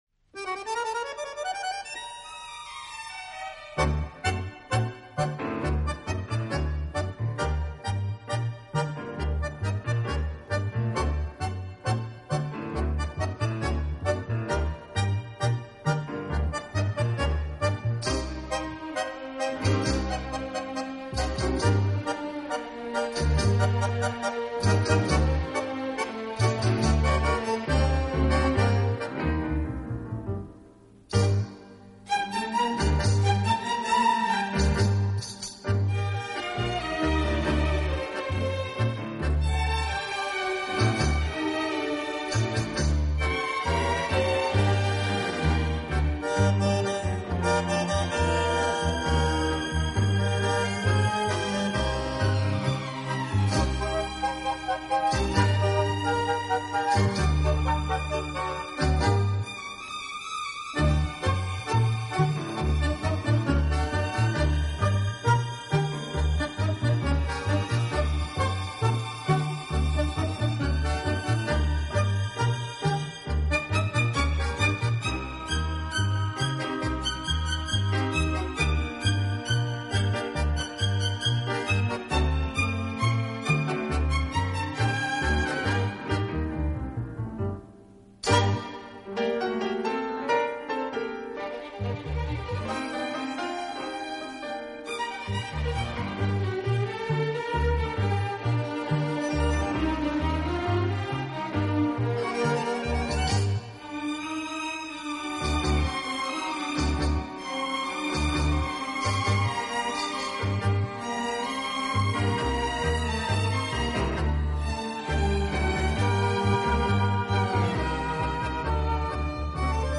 Studio/Live: Studio
Genre, Style: Instrumental, Tango
探戈，源于阿根廷，2/4拍子。